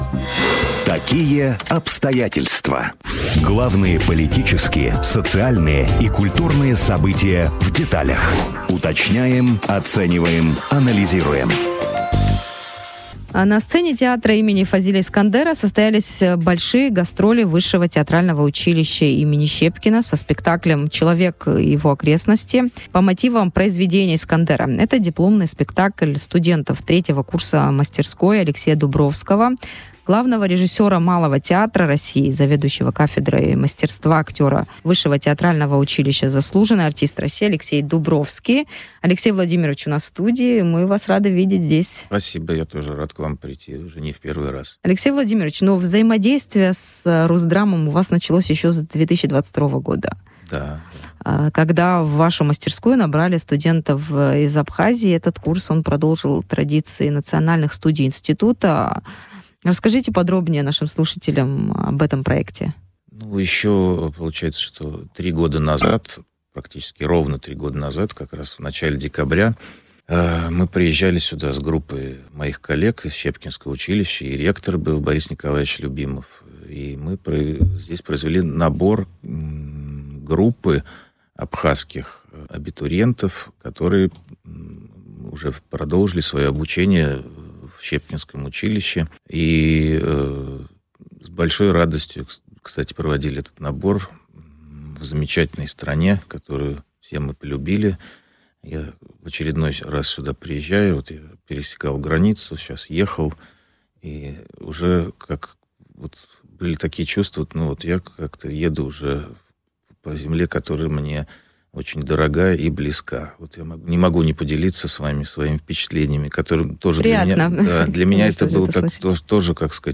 в эфире радио Sputnik